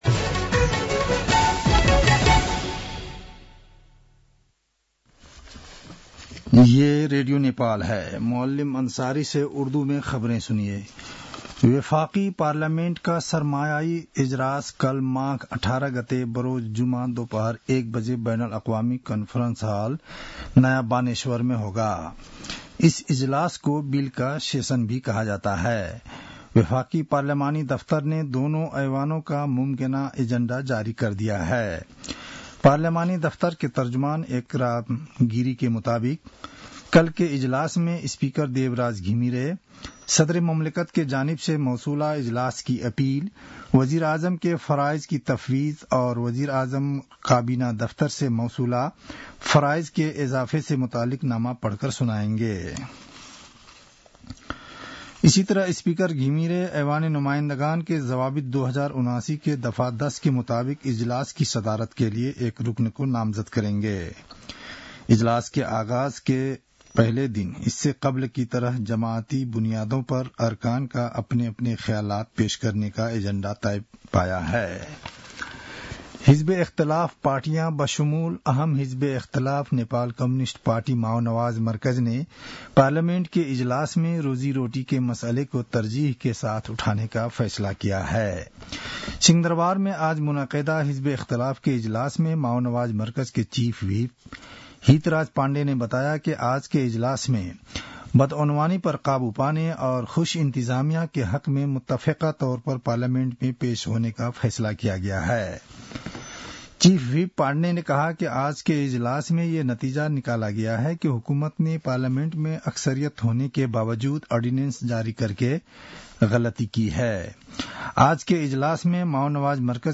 An online outlet of Nepal's national radio broadcaster
उर्दु भाषामा समाचार : १८ माघ , २०८१
Urdu-news-10-17.mp3